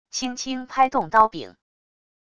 轻轻拍动刀柄wav音频